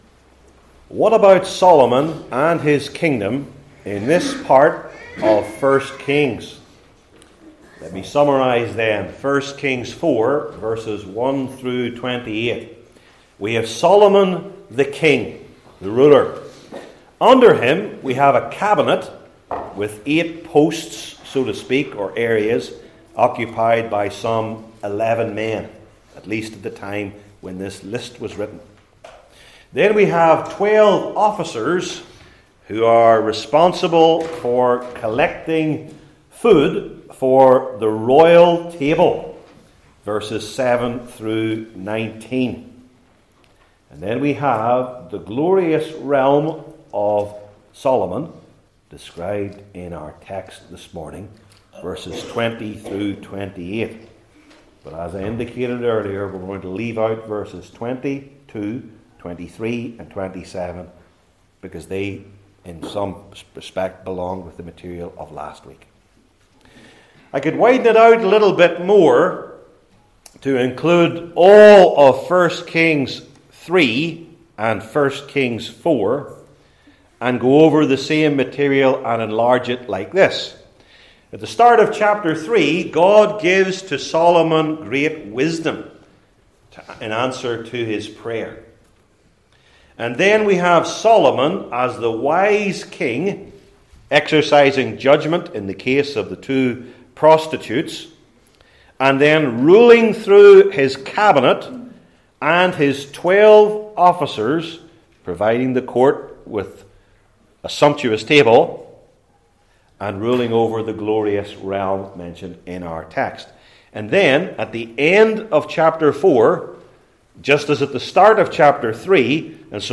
I Kings 4:20-28 Service Type: Old Testament Sermon Series I. His Prosperous Kingdom II.